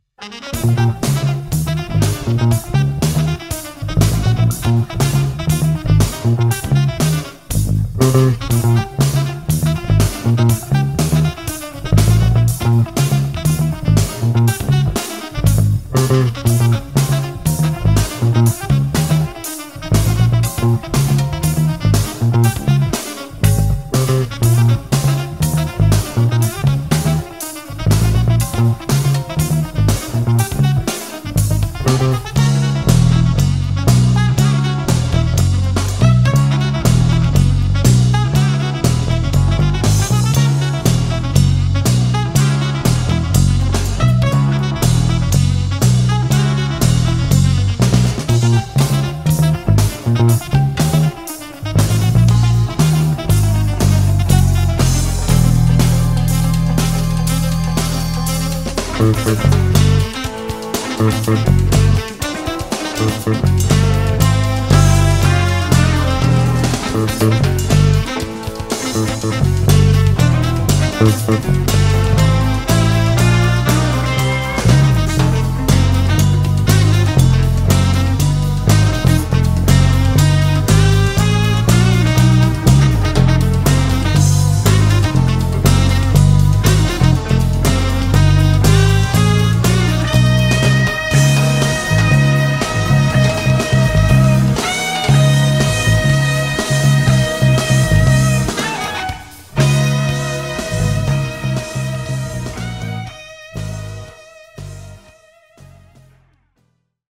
916 Bicentennial Recreation Bass Pickup in Mix